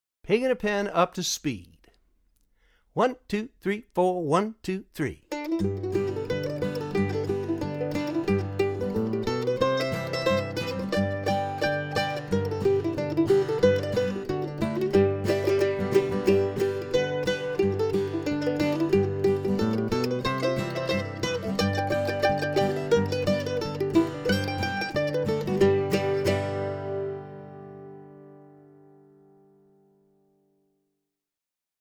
DIGITAL SHEET MUSIC - MANDOLIN SOLO
Traditional Mandolin Solo
both slow and regular speed